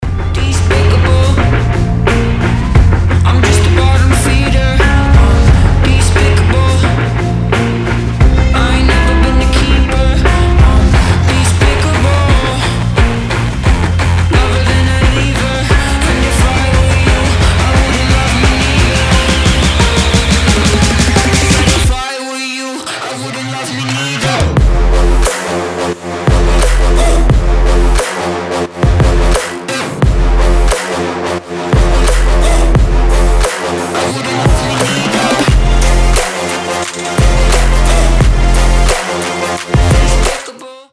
нарастающие
Trap
качающие
Alternative Hip-hop